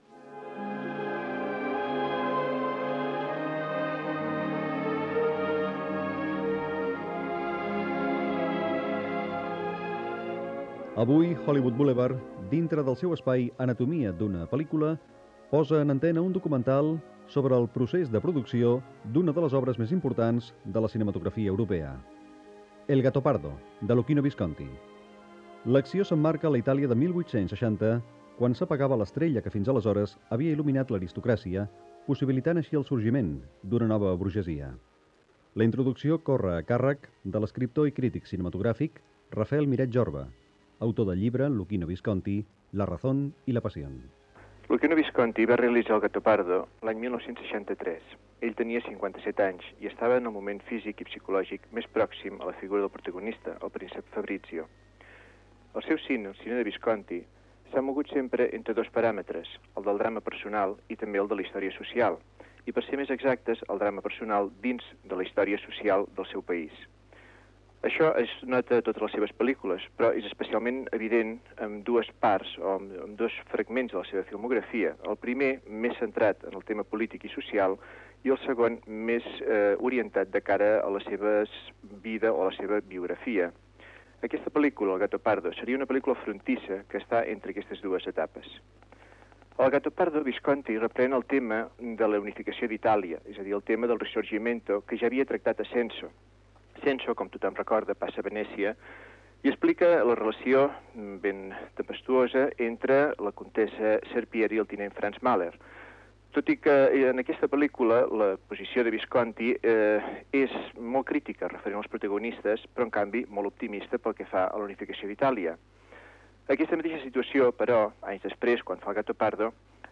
Cultura
FM